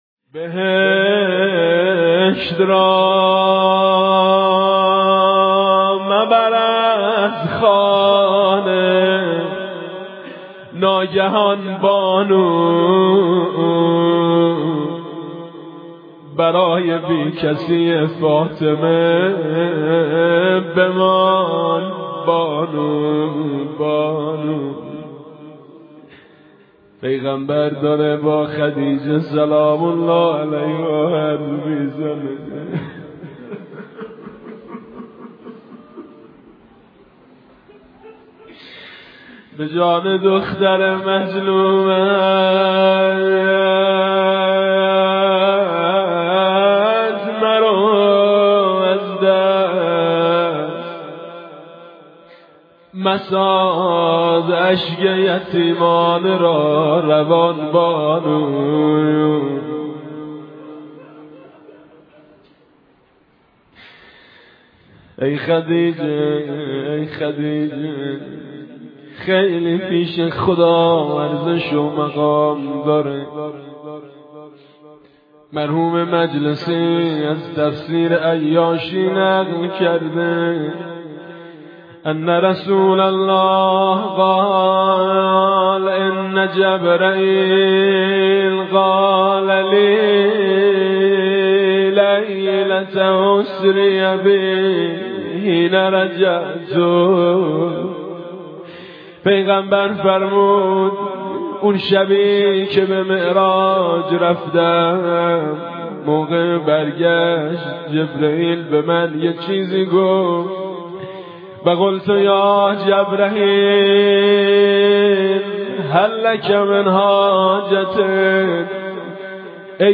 متن روضه وفات حضرت خدیجه (س)